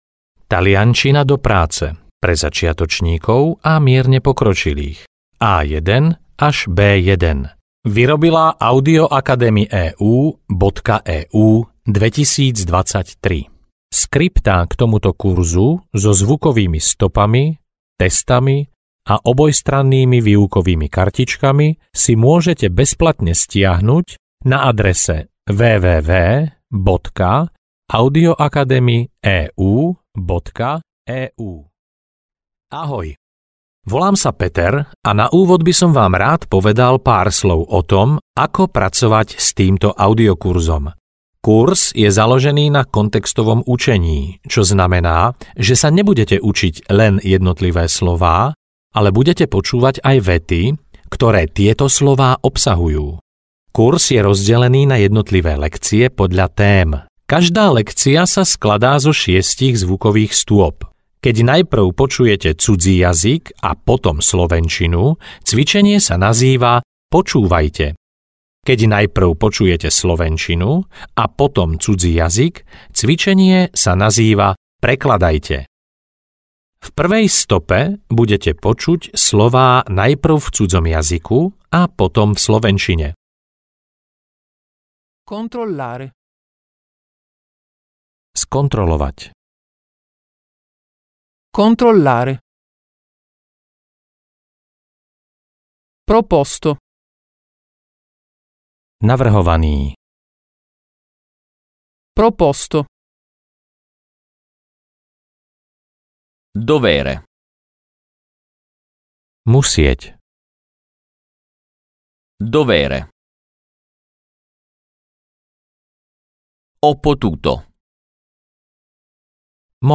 Taliančina do práce A1-B1 audiokniha
Ukázka z knihy